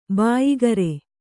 ♪ bāyigare